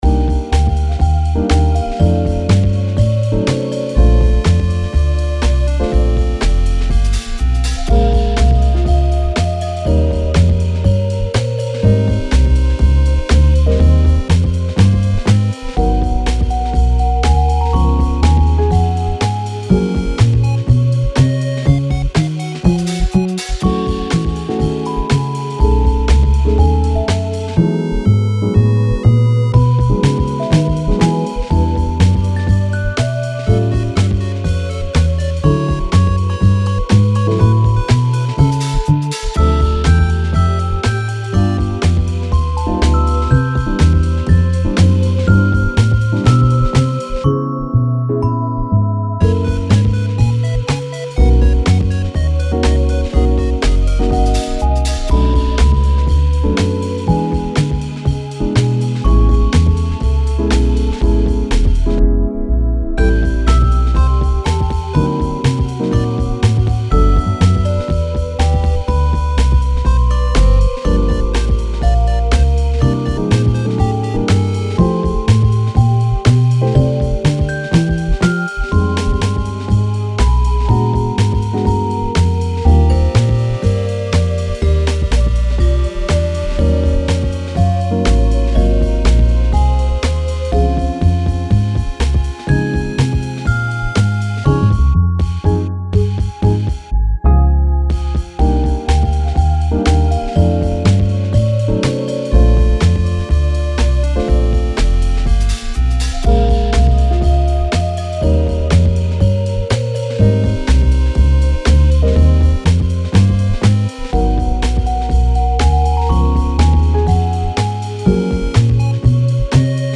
Download 10分ループVer.